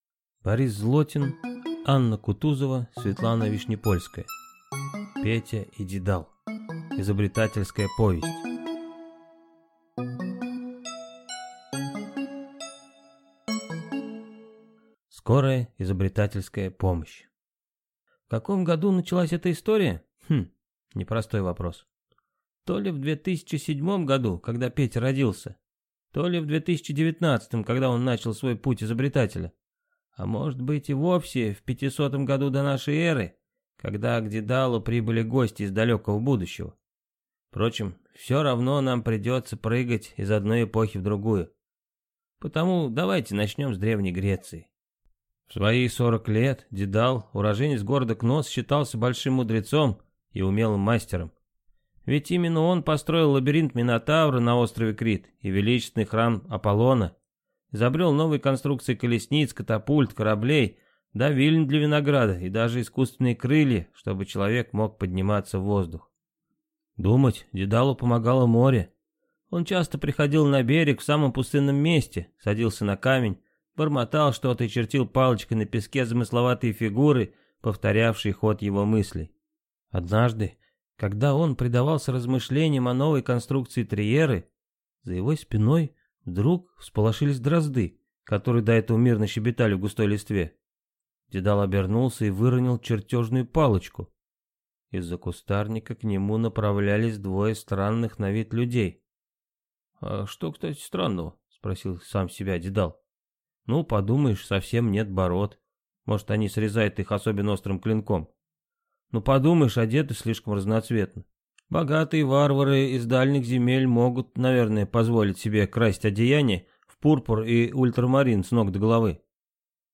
Аудиокнига Петя и Дедал. Изобретательская повесть | Библиотека аудиокниг